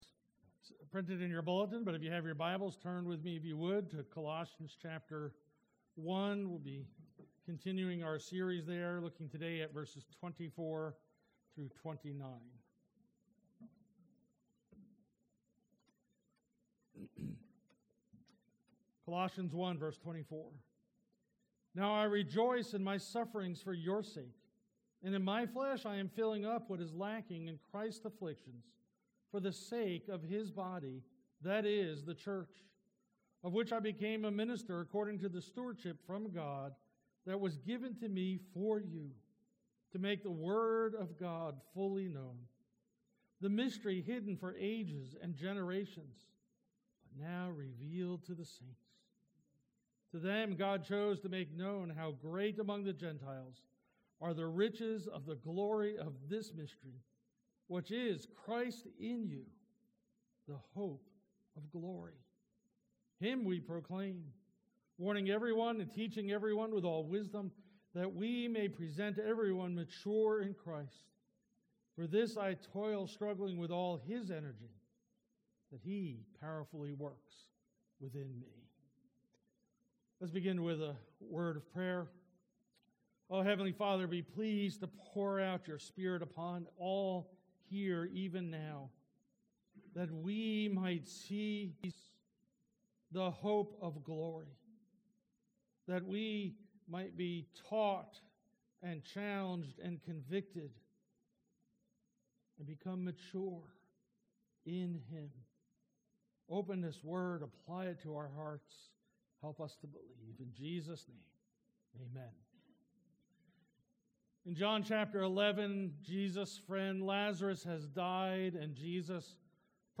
Trinity Presbyterian Church Sermons